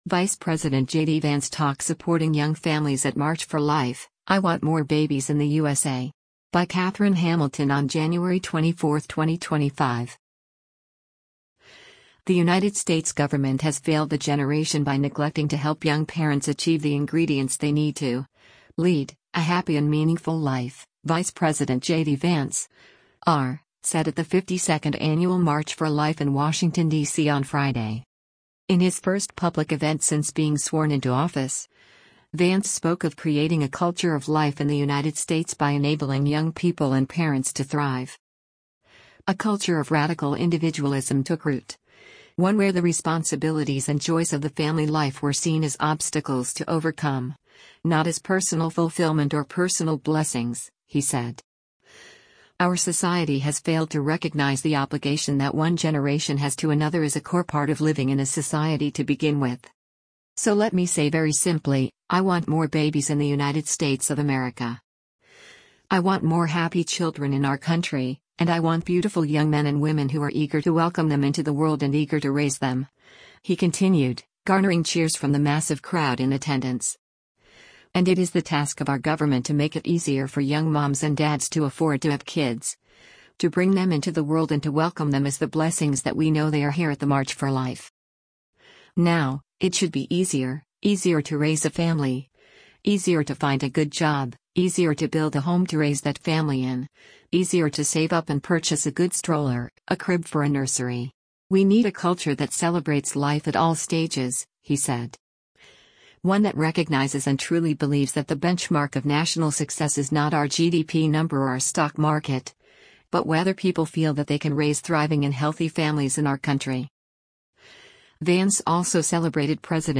The United States government has “failed a generation” by “neglecting to help young parents achieve the ingredients they need to [lead] a happy and meaningful life,” Vice President JD Vance (R) said at the 52nd annual March for Life in Washington, D.C. on Friday.